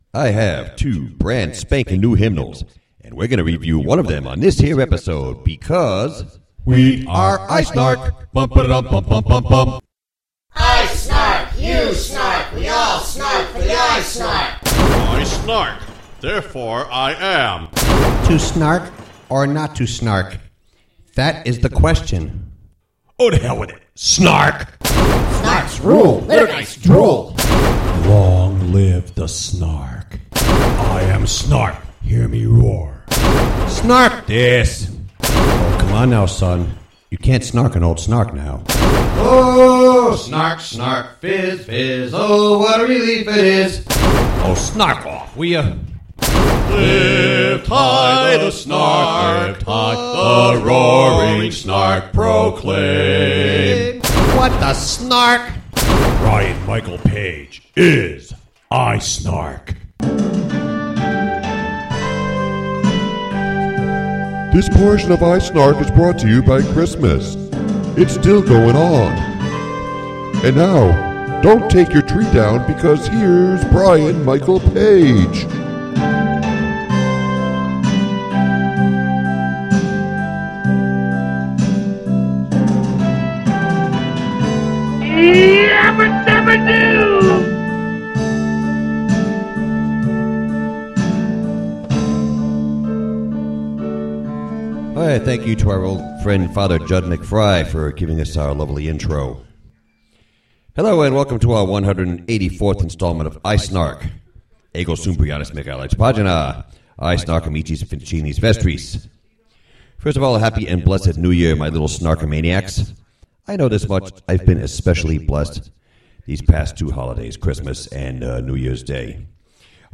I have in my possession a copy of the latest edition of the St. Michael Hymnal , and you're going to hear a review of it right here on iSNARK! Also, we hear from technologically created Christus Vincit and iSNARK! guests past and present as to how they celebrated the New Year.
Puer Natus in Bethlehem (Chant, Mode I), sung by Basso Profundo, the Singing Fish 2.